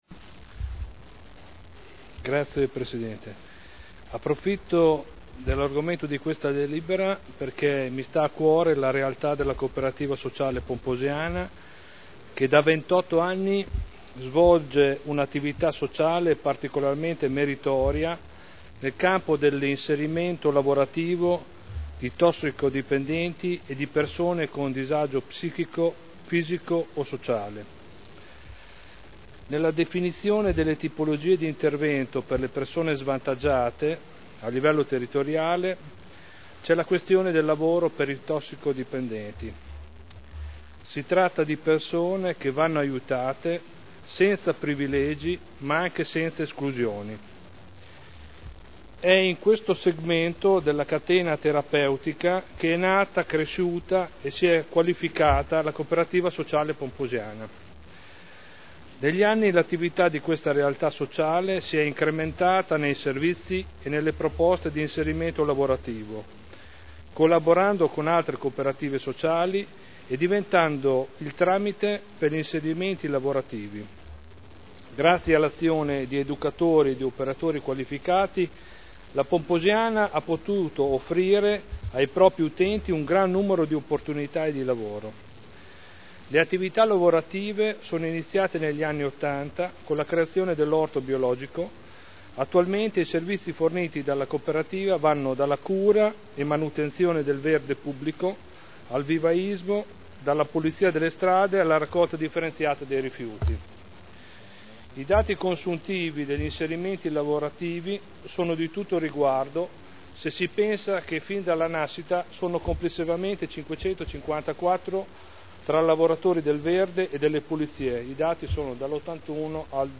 Seduta del 10/12/2009. Nulla osta al rilascio di permesso di costruire in deroga agli strumenti urbanistici (Art. 31.23 RUE) – Richiesta di permesso di costruire 1163/2009 presentata dalla Pomposiana Cooperativa Sociale a responsabilità limitata per ampliamento ad uso deposito attrezzature agricole
Audio Consiglio Comunale